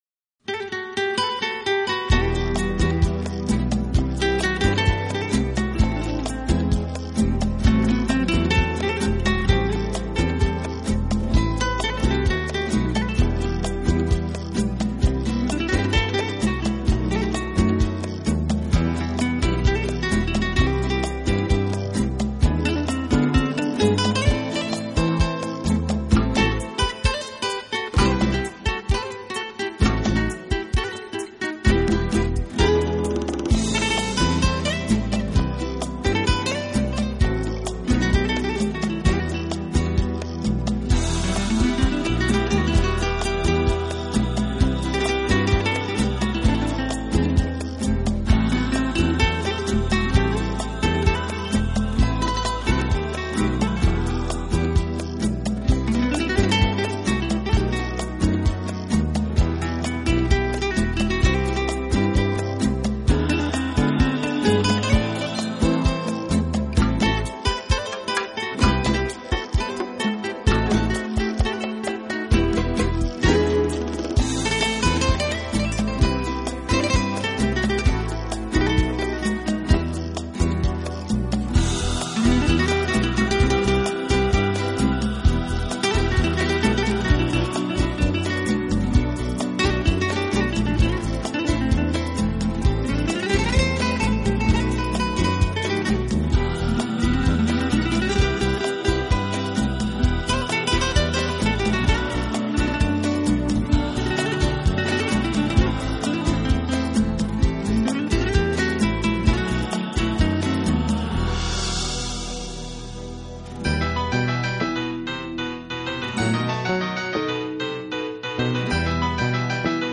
吉他专辑
音乐融合了拉丁-吉普赛-爵士元素而形成他独特的风格，在热情洋溢的佛朗明